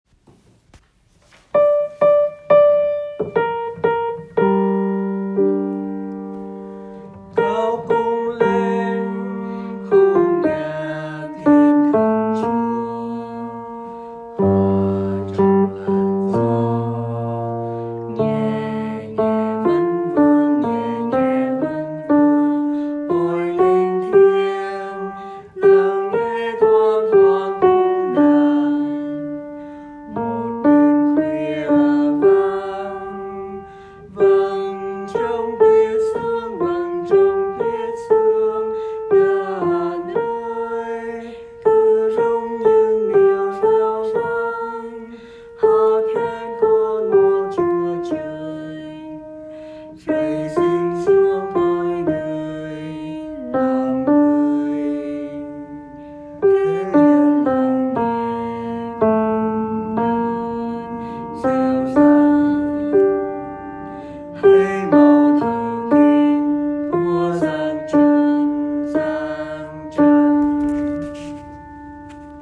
Rước Lễ Cao Cung Lên (Gm/T70/) Youtube
Bè Nam